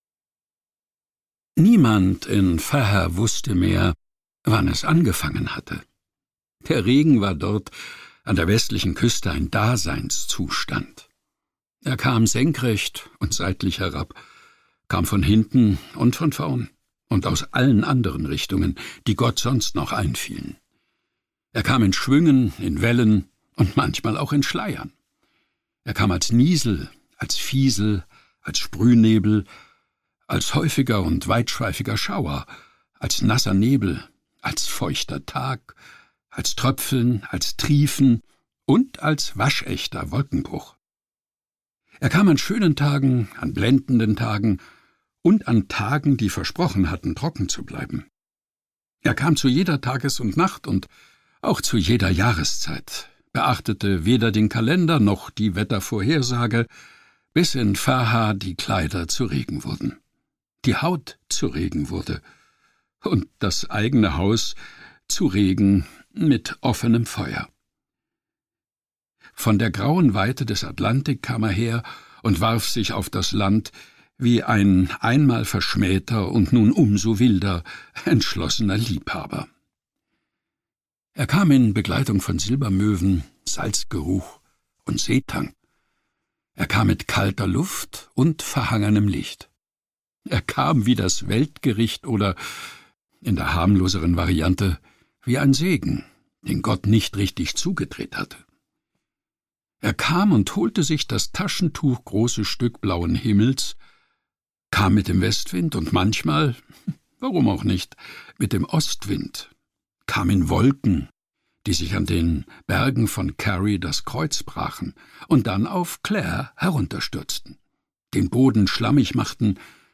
Details zum Hörbuch